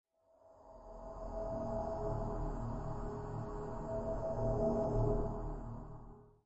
Tag: 环境 噪声 记录 样品